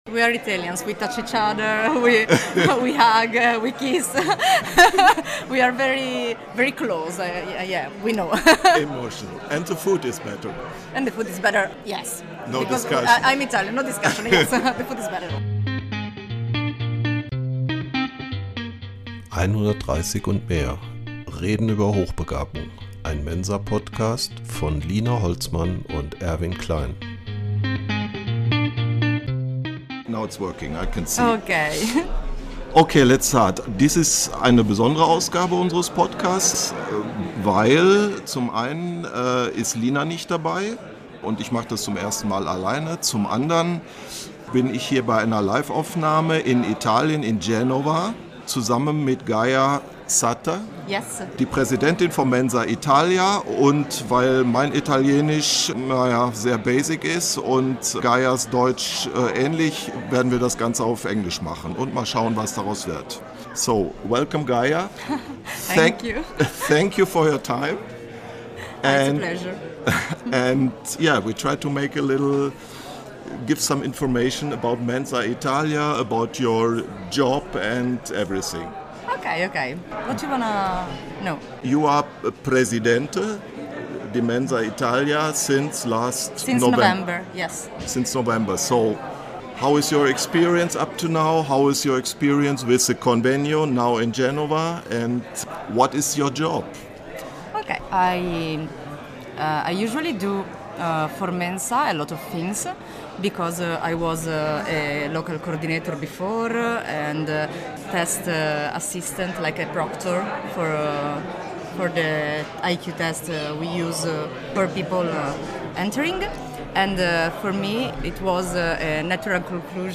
Beschreibung vor 2 Tagen Mensa Italia Convegno in Genova. Das Jahrestreffen der italienischen Mensa. 350 Mitglieder – von insgesamt knapp 2.700 in ganz Italien – treffen sich, um vor allem Spaß zu haben.